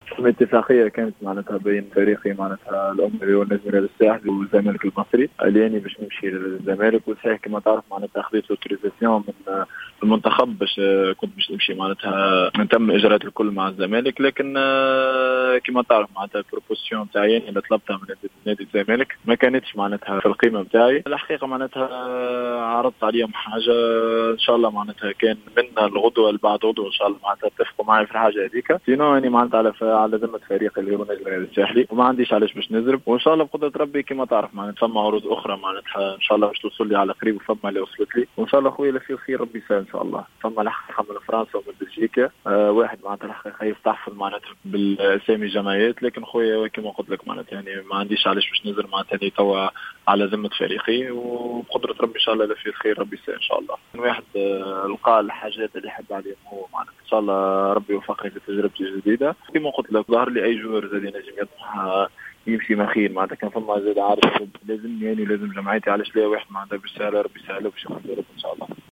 أكد لاعب النجم الساحلي حمدي النقاز بتصريح خاص بجوهرة أف ام أن صفقة إنتقاله للزمالك المصري لم يتم حسمها بعد بما أنه قد قدم شروطه للفريق المصري و مازال بإنتظار القرار النهائي الذي قد يتخذ خلال اليومين القادمين.